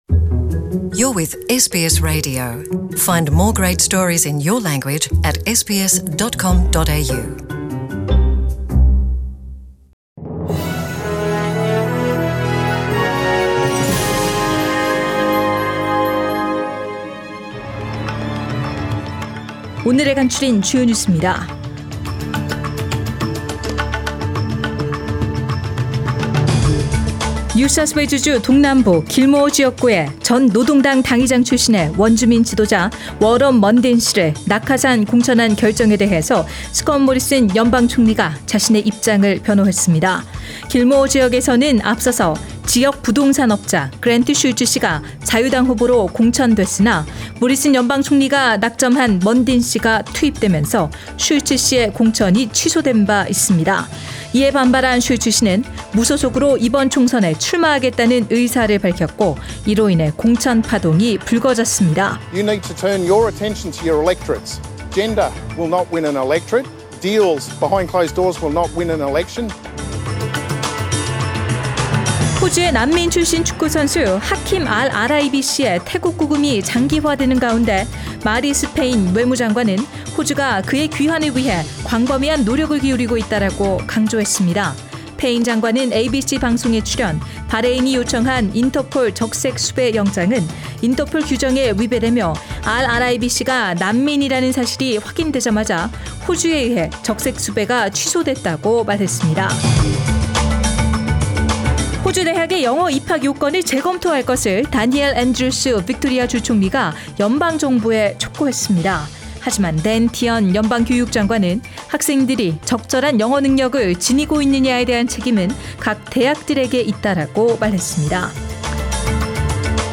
SBS 한국어 뉴스 간추린 주요 소식 – 1월 23일 수요일
2019년 1월 23일 수요일 저녁의 SBS Radio 한국어 뉴스의 간추린 주요뉴스를 팟 캐스트를 통해 접하시기 바랍니다.